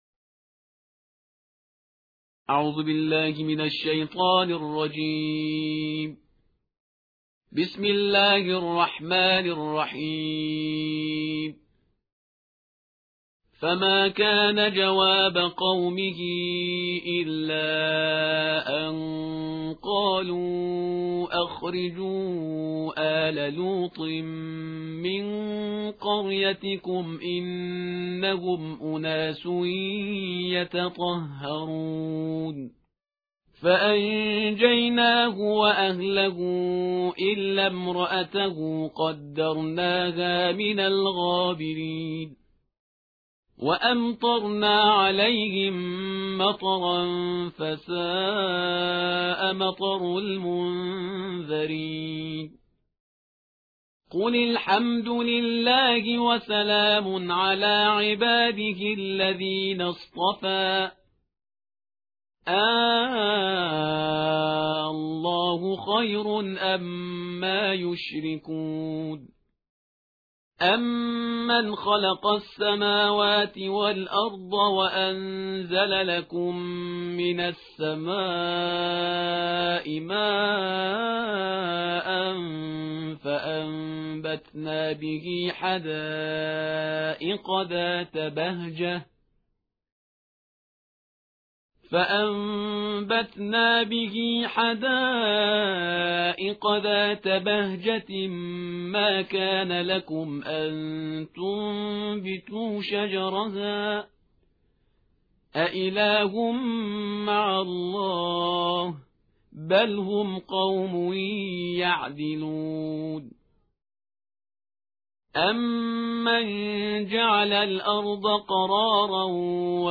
ترتیل جزء بیست قرآن کریم/استاد پرهیزگار